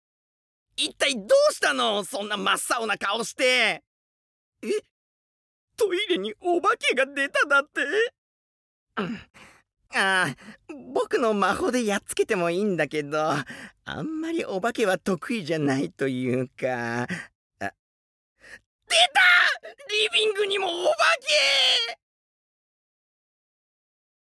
ボイスサンプル
台詞4